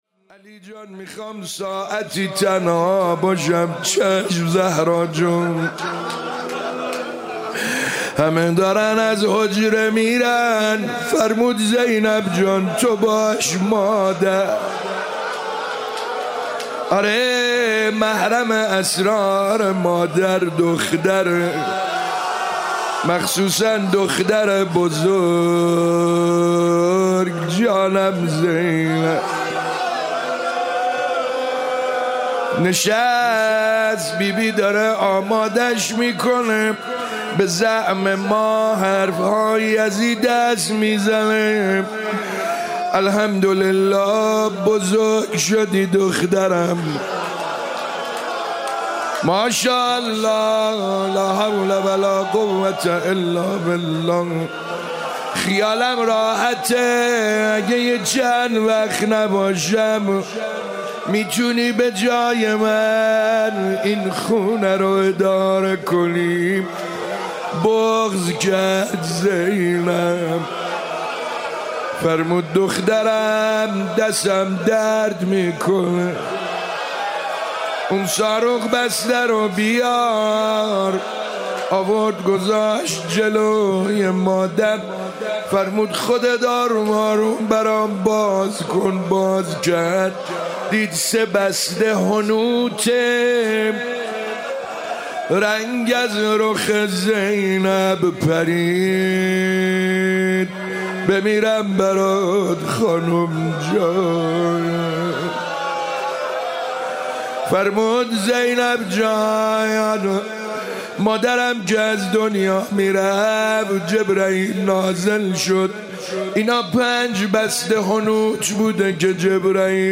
هفتگی 19 بهمن 96 - روضه